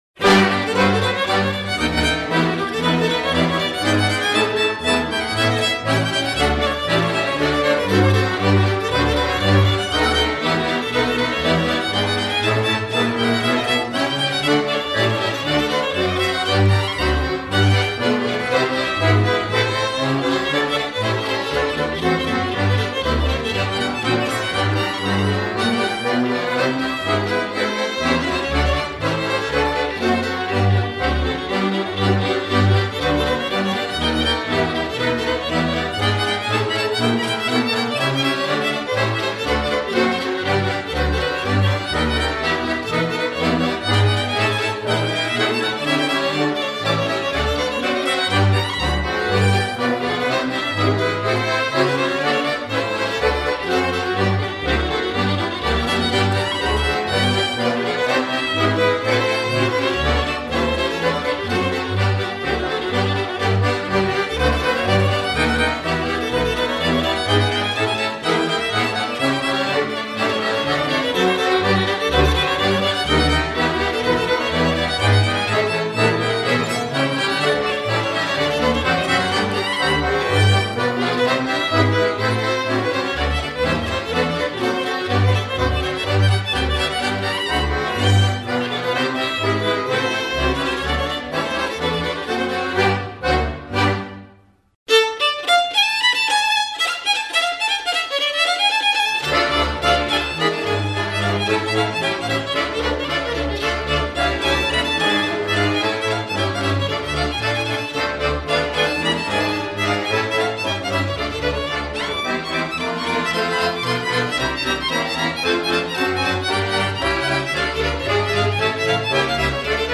vioară